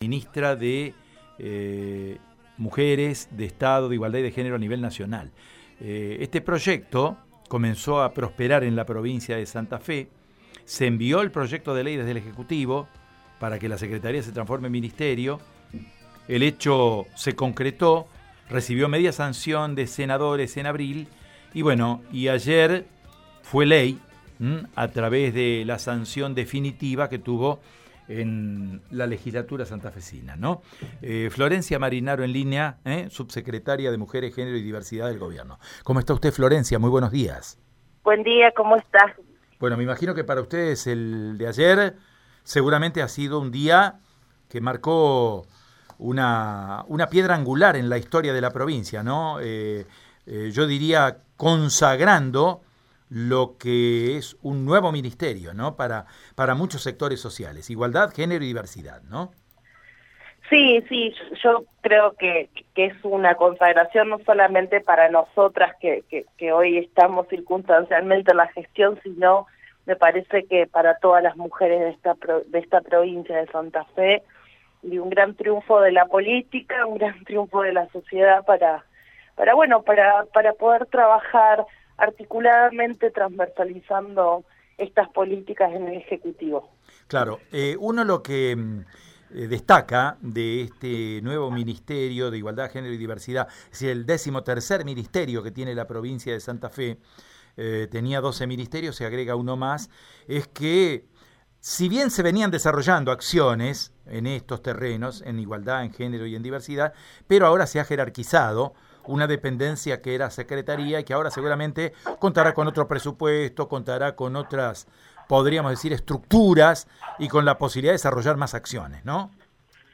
En diálogo con Radio EME, la actual subsecretaria del área, Florencia Marinaro, indicó que la aprobación del proyecto significa «una consagración, no solamente para nosotras que hoy estamos circunstancialmente en la gestión, sino me parece que para todas las mujeres de esta provincia».